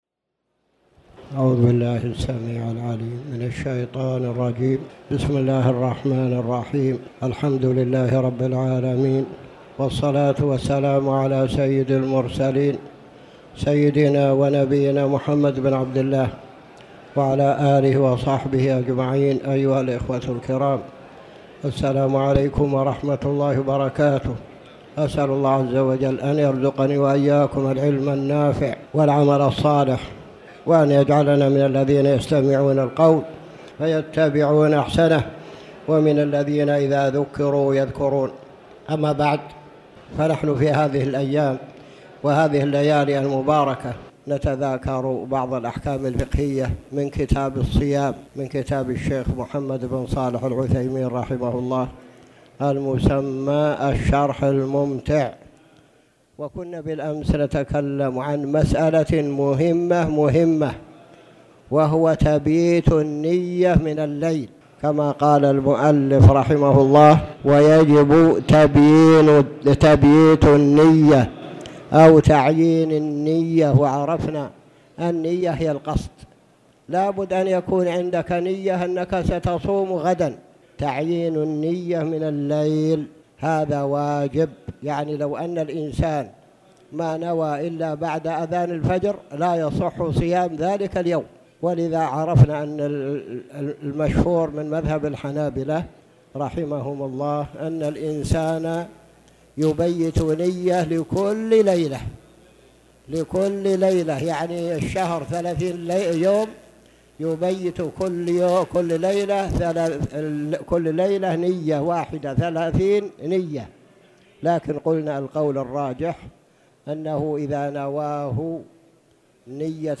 تاريخ النشر ١٨ جمادى الأولى ١٤٣٩ هـ المكان: المسجد الحرام الشيخ